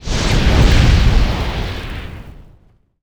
Magic_SpellFire02.wav